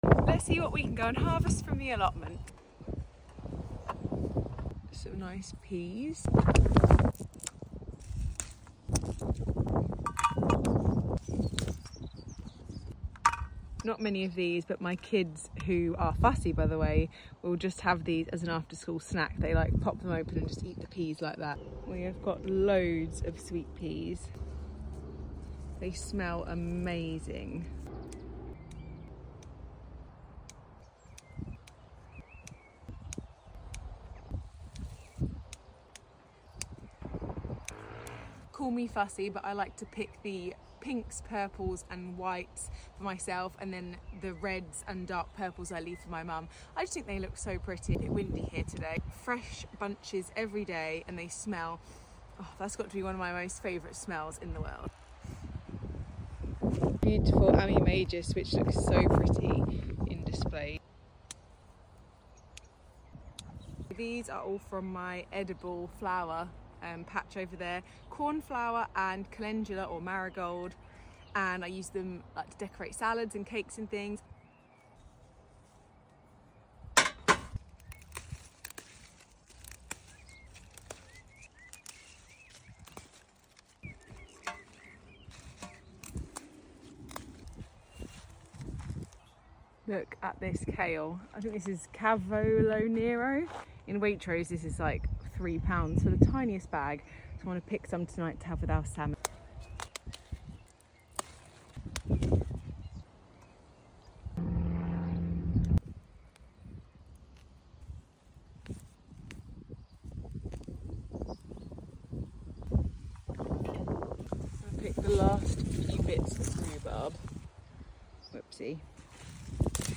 I find the sounds of allotment cropping so satisfying!! Is allotment/gardening asmr a thing??